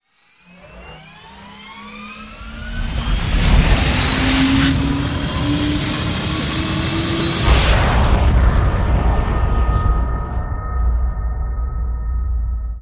ships / Movement / launch7.wav
launch7.wav